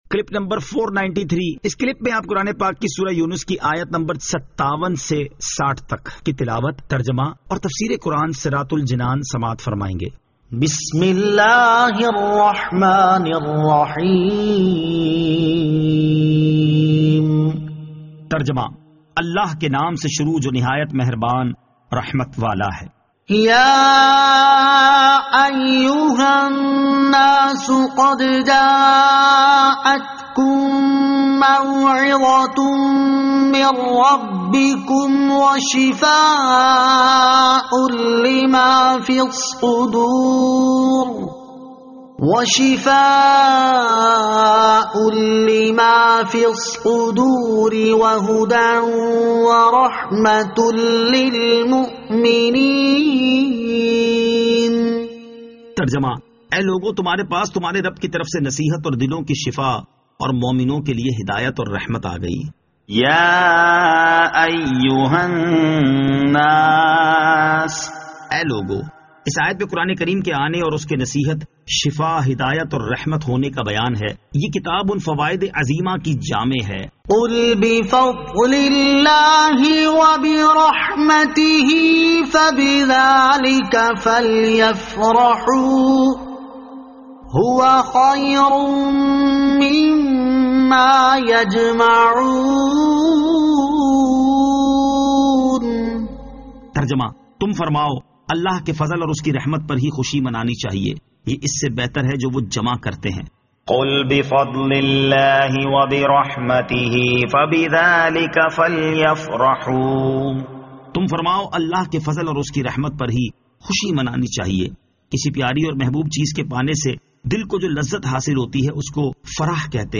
Surah Yunus Ayat 57 To 60 Tilawat , Tarjama , Tafseer